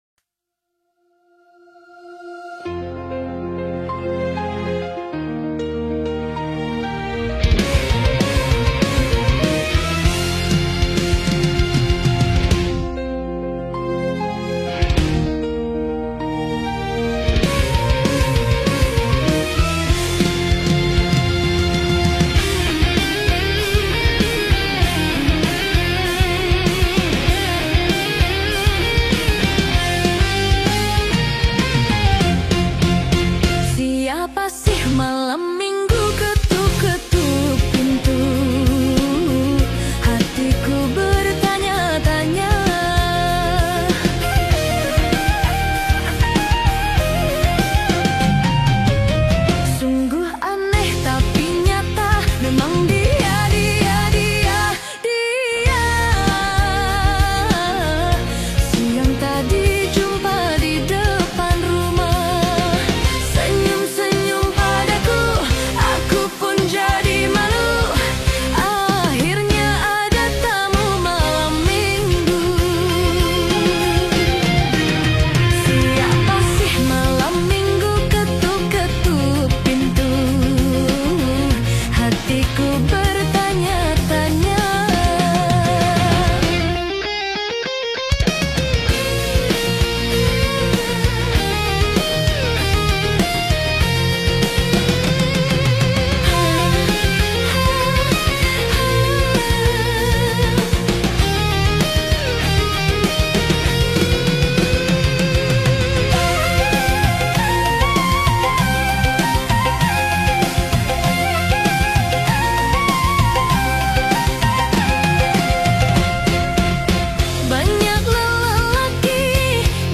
RockDut Cover